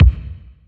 • Subtle Reverb Kick Drum One Shot C Key 643.wav
Royality free bass drum tuned to the C note. Loudest frequency: 233Hz
subtle-reverb-kick-drum-one-shot-c-key-643-HYi.wav